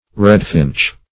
redfinch - definition of redfinch - synonyms, pronunciation, spelling from Free Dictionary Search Result for " redfinch" : The Collaborative International Dictionary of English v.0.48: Redfinch \Red"finch`\ (-f[i^]nch`), n. (Zool.)